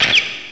cry_not_joltik.aif